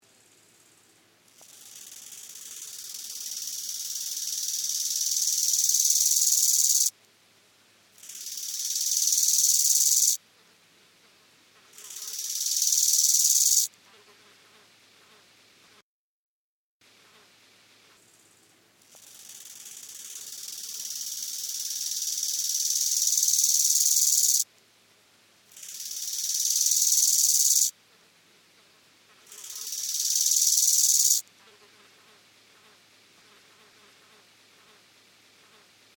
Nachtigall-Grashüpfer – Natur erleben – beobachten – verstehen
Sein charakteristischer Gesang wird von vielen Menschen mit Sommerwiesen assoziiert. Er ist ziemlich laut, so dass man ihn im Umkreis von einigen Metern hören kann.
Hören Sie sich hier den charakteristischen Gesang des Nachtigall-Grashüpfer an.
Typische-Aufnahme-Nachtigall-Grashuepfer.mp3